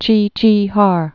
(chēchēhär) also Tsi·tsi·har (tsētsē-)